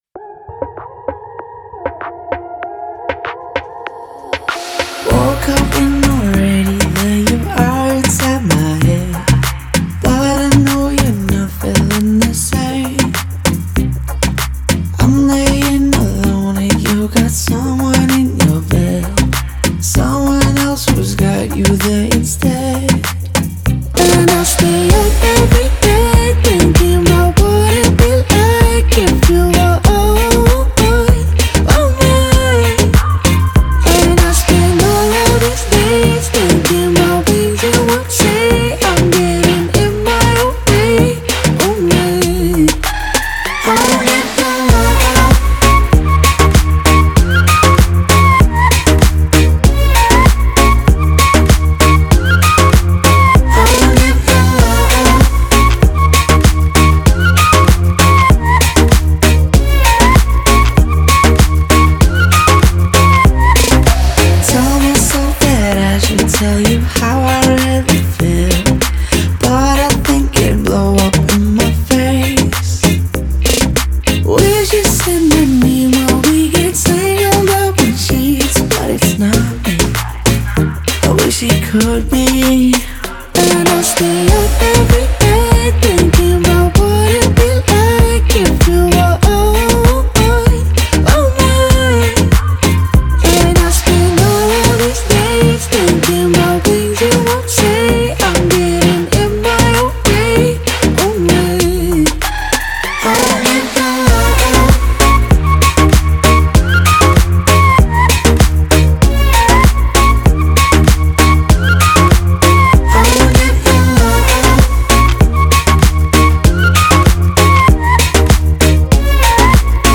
это запоминающаяся композиция в жанре электро-поп